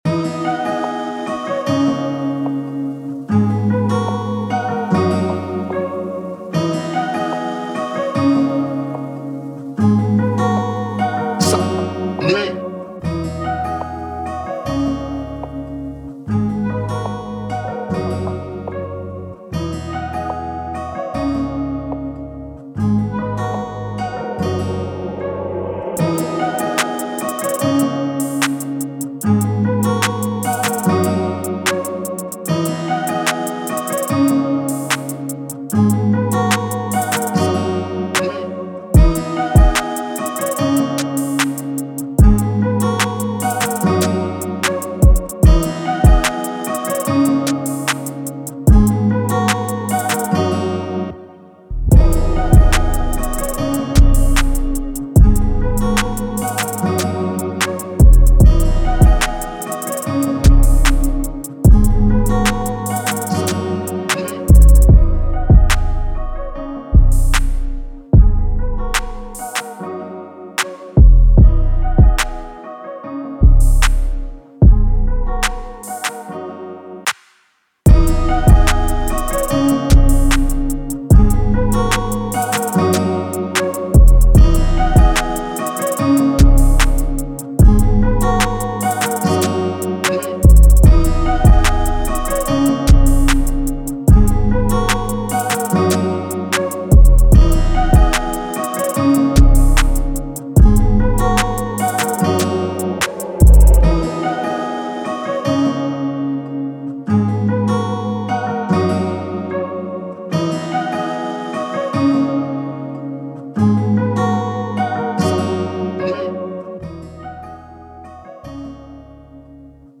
Energetic, Positive, Spiritual
Acoustic Guitar, Drum, Piano